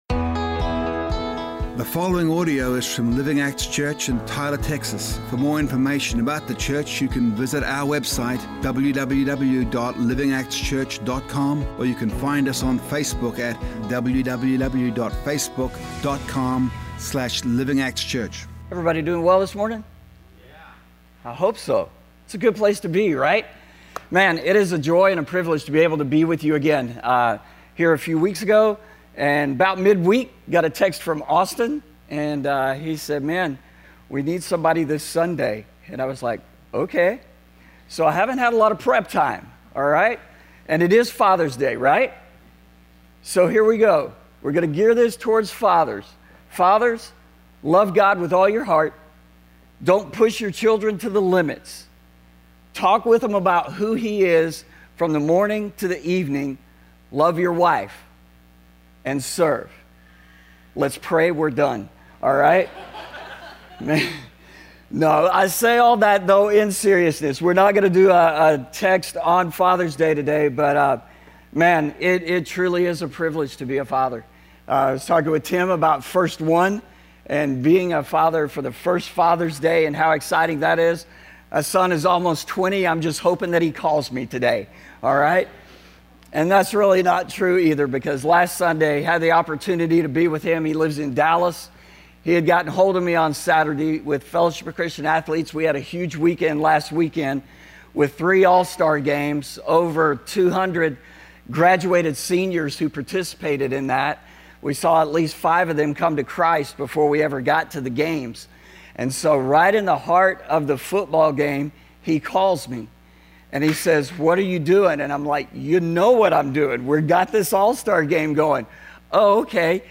A message from the series "Single."